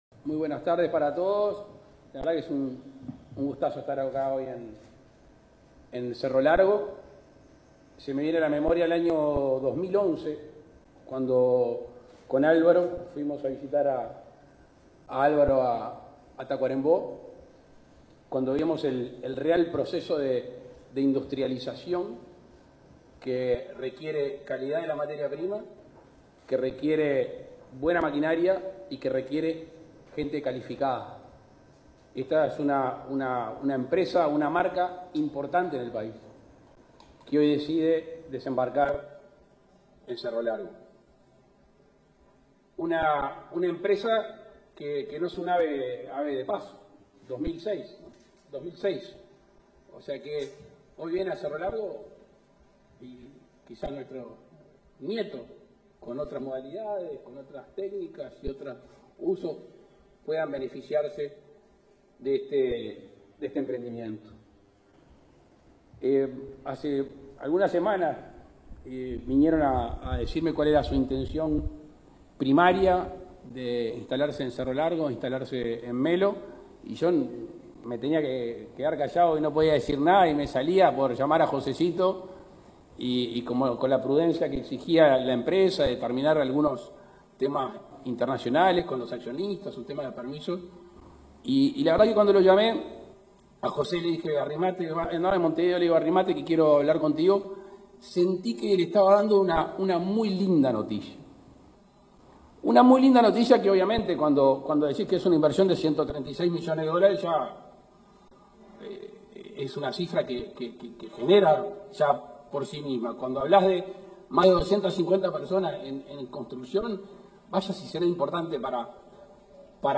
Palabras del presidente de la República, Luis Lacalle Pou, en Melo
El centro productivo generará unos 300 empleos. En la oportunidad, se expresó el presidente de la República, Luis Lacalle Pou.